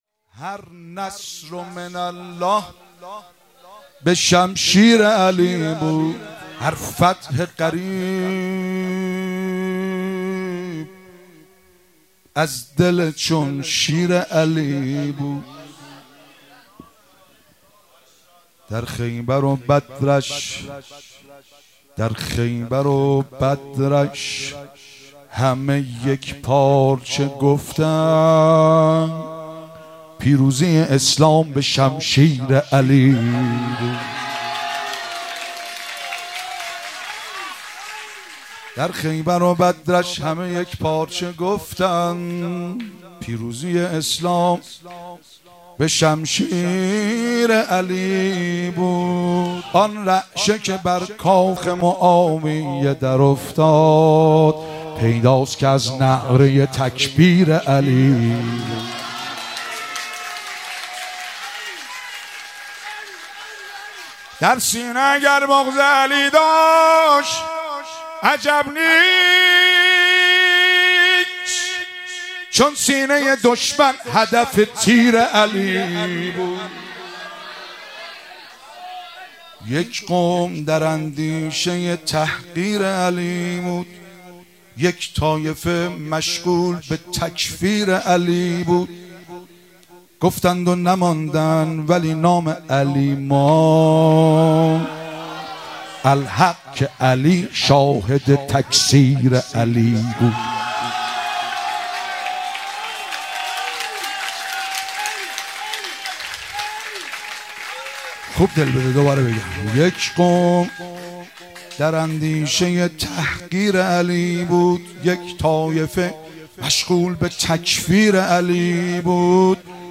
جشن میلاد امام حسن عسکری (ع)- آبان 1401
شعرخوانی- هر نصر من الله به شمشیر علی بود